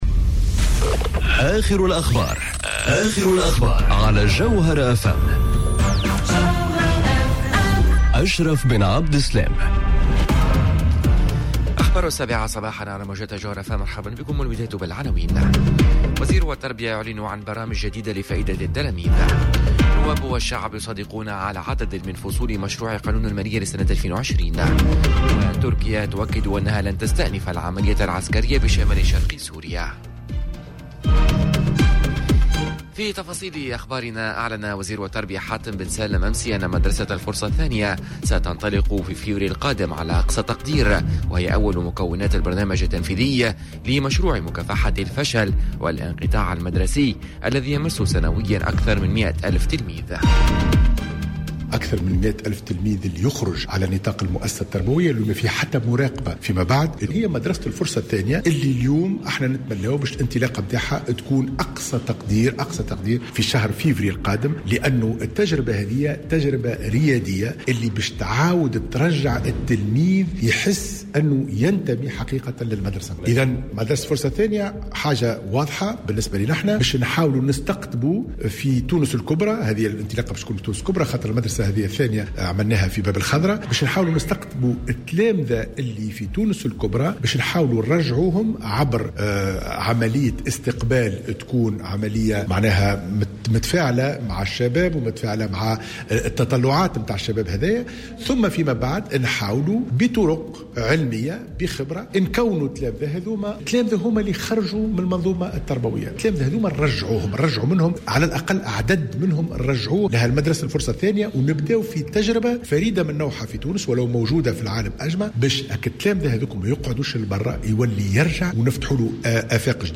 نشرة أخبار السابعة صباحا ليوم الثلاثاء 26 نوفمبر 2019